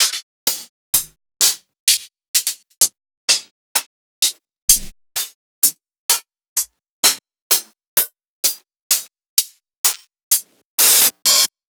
HI HATS.wav